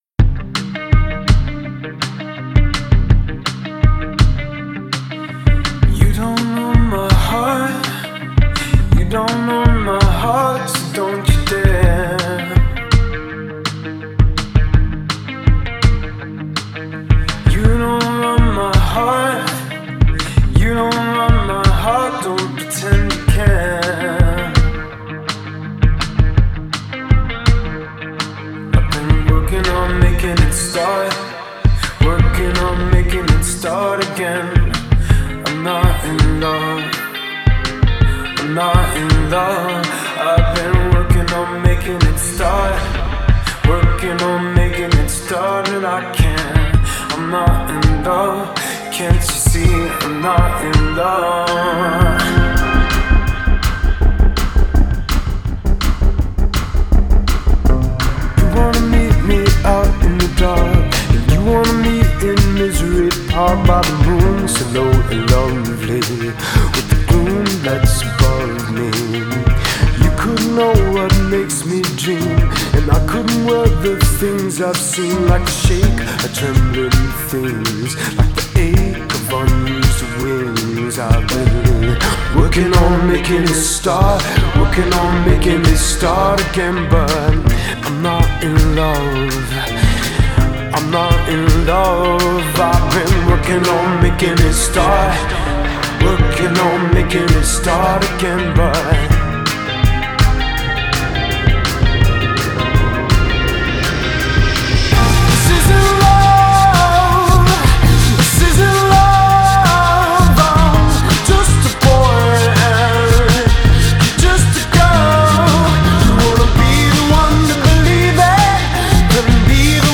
lush 80’s inspired sound